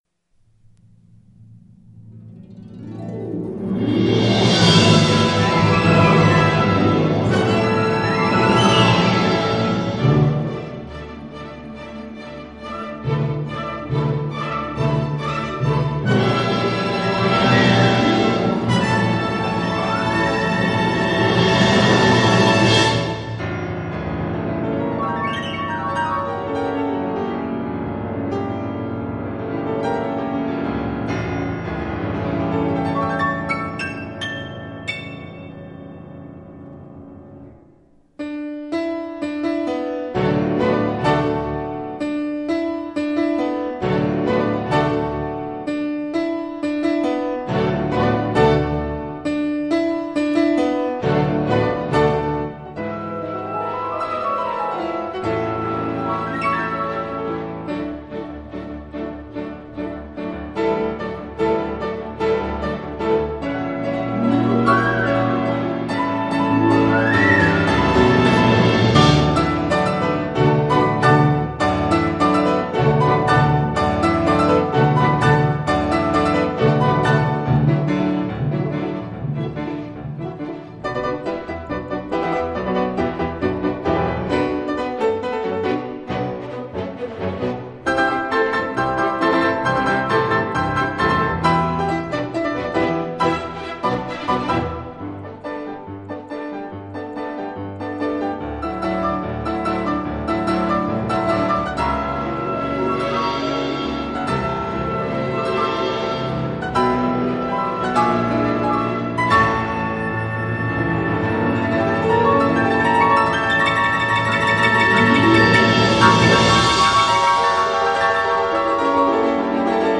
钢琴协奏曲